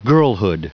Prononciation du mot girlhood en anglais (fichier audio)
Prononciation du mot : girlhood